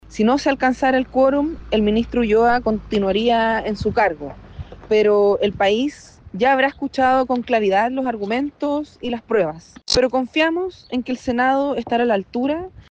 La diputada del Frente Amplio, Carolina Tello, es una de las tres parlamentarias que presentará la acusación. Dijo que si no se aprueba, al menos todo Chile podrá escuchar los argumentos y la evidencia.